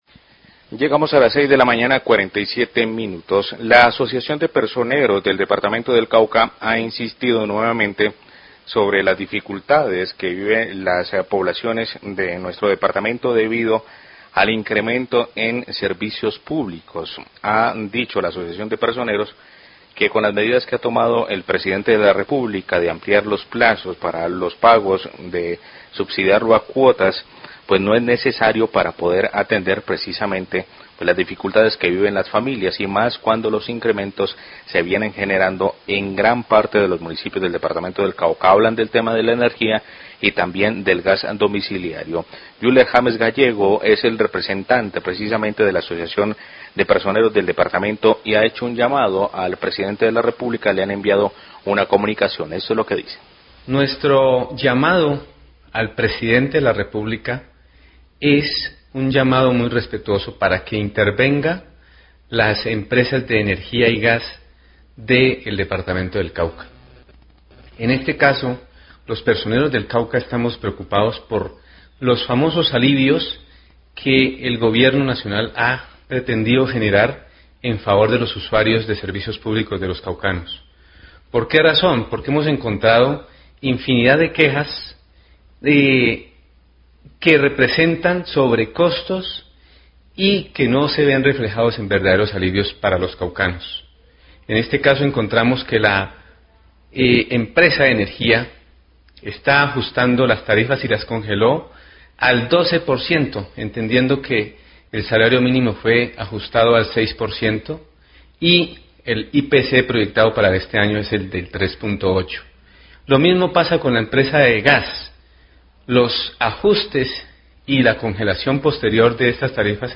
Radio
Leen carta de la Asociación de Personeros del Cauca pidiendo al Pdte Duque que intervenga las empresas de energía y gas del Cauca por los altos cobros del servicio a pesar de los alivios decretados por el gobierno por pandemia del coronavirus